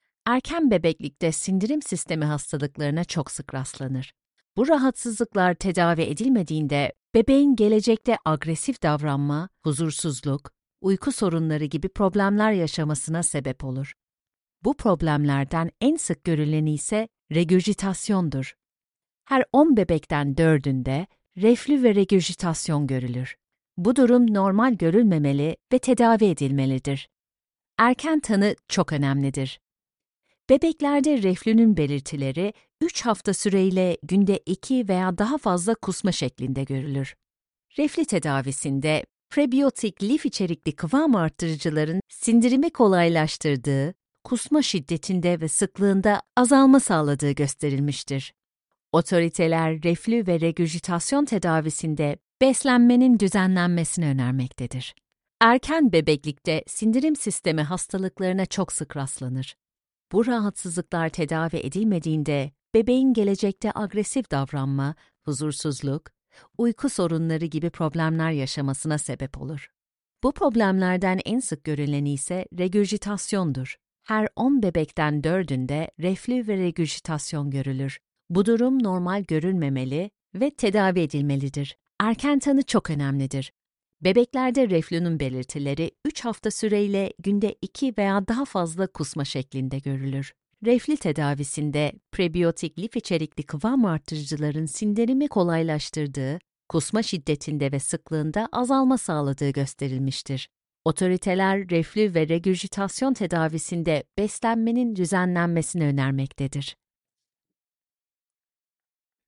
Amicale, Chaude, Corporative
E-learning